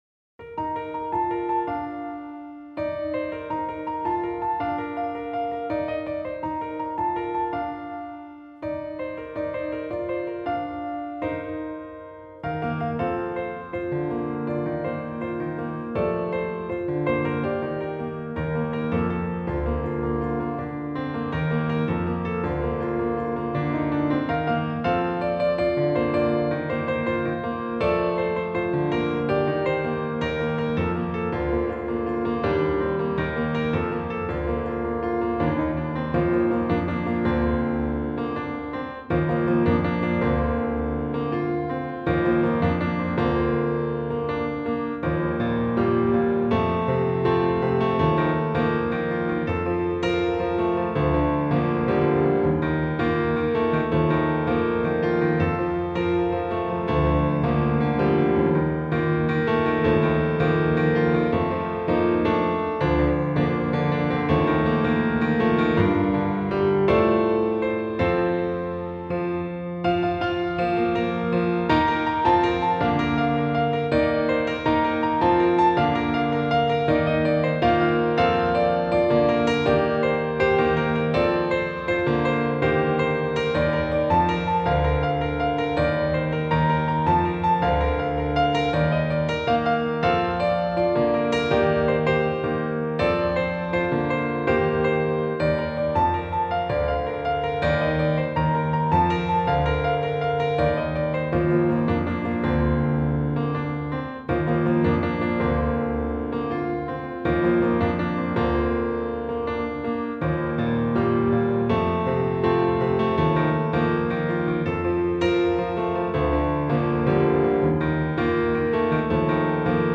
great upbeat piano solo